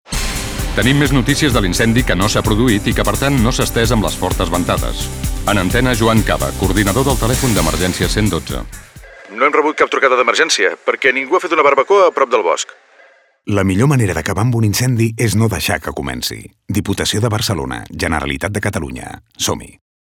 Així, els espots de ràdio i televisió tenen un format proper al documental, que permeten seguir el testimoni de diverses persones, com pagesos, ciutadans particulars o membres dels equips d’emergència, entre d’altres.
Falca ràdio. Coordinadora i brossa